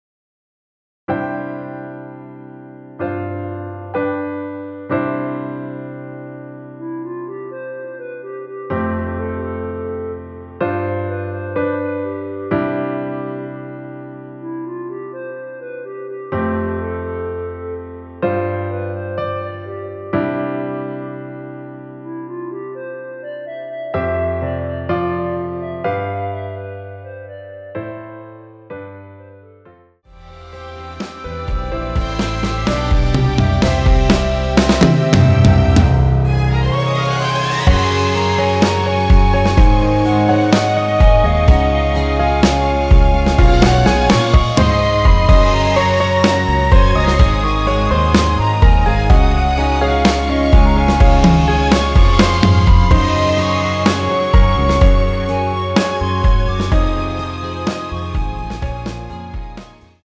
전주 없이 시작하는 곡이라 전주 만들어 놓았습니다.(미리듣기 확인)
원키에서(+3)올린 멜로디 포함된 MR입니다.
앞부분30초, 뒷부분30초씩 편집해서 올려 드리고 있습니다.
중간에 음이 끈어지고 다시 나오는 이유는